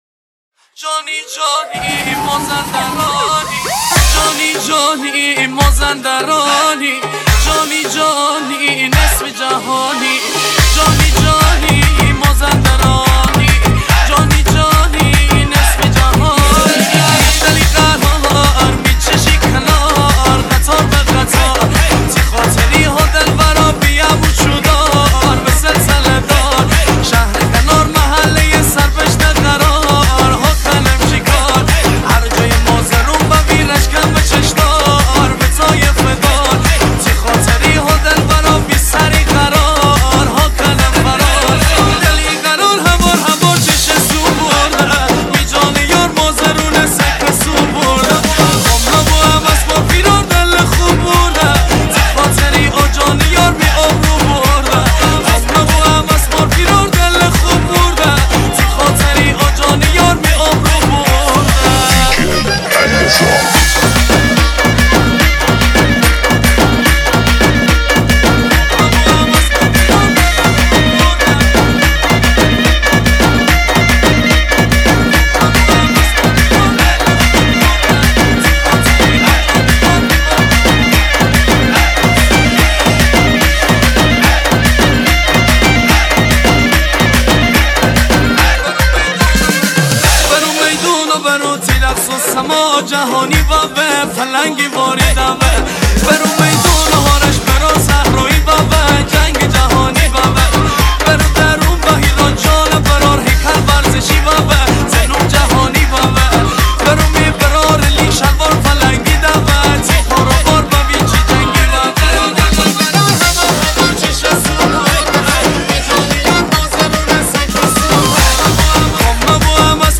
ریمیکس شاد مازندرانی ماشین و عروسی